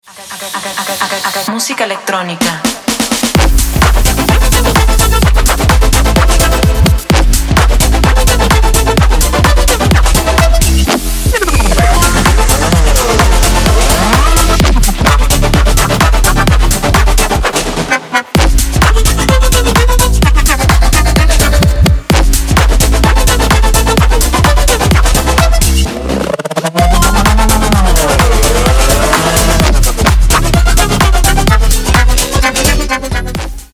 Рингтоны Электроника